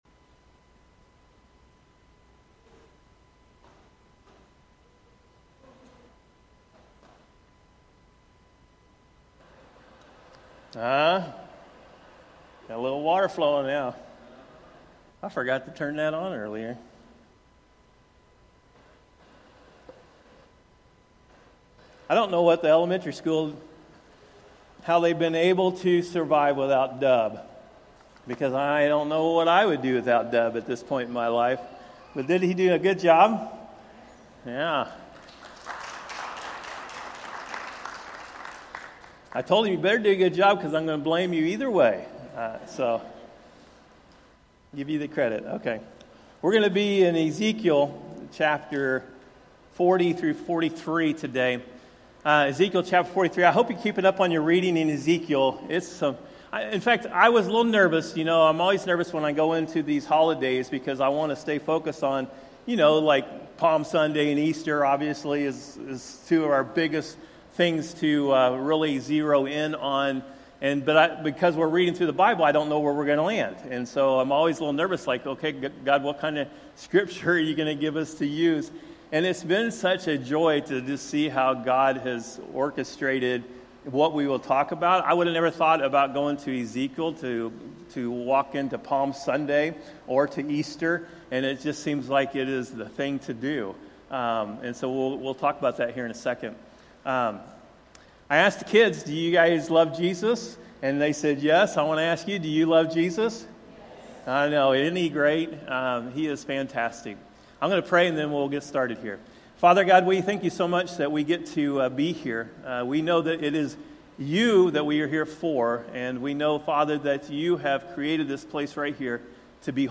Sunday Service.
Sermons by Westside Christian Church